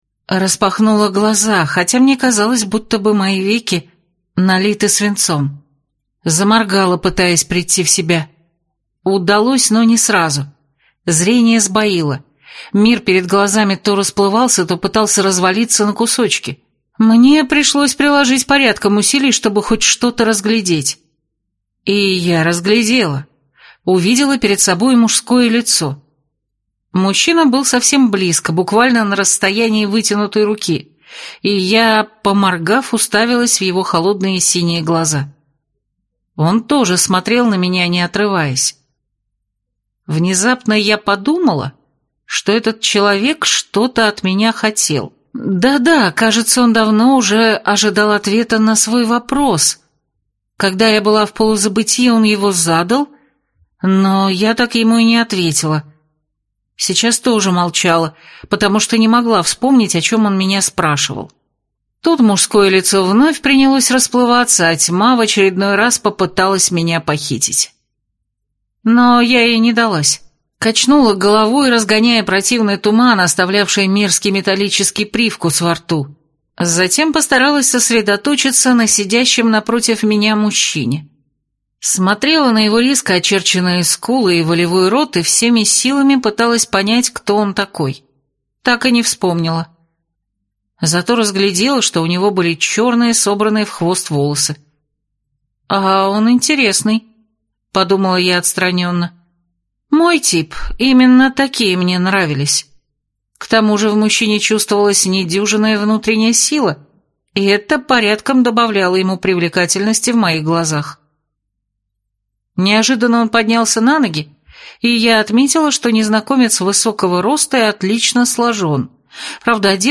Аудиокнига Маленькая хозяйка большой таверны | Библиотека аудиокниг